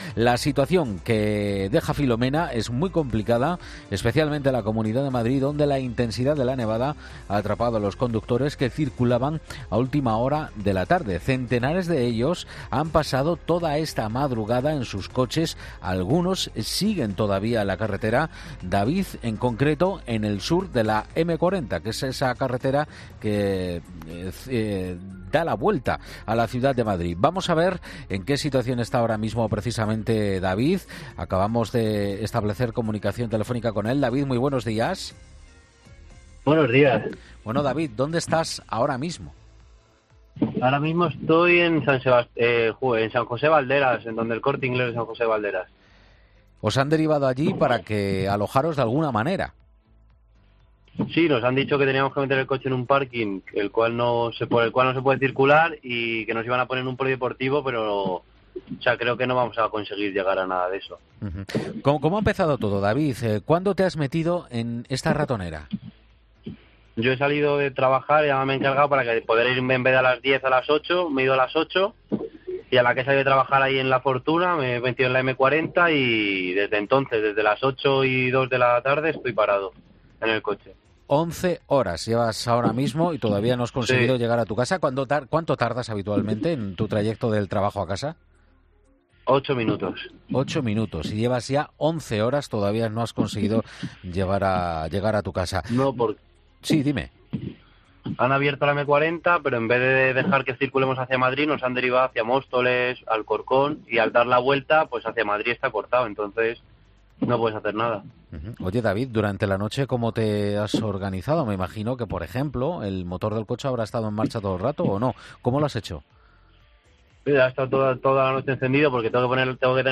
Numerosos conductores atrapados por la tremenda borrasca de nieve que está afectando a gran parte de España han contado en COPE lo que están viviendo
Conductores atrapados en las carreteras de Madrid por la nieve de la borrasca Filomena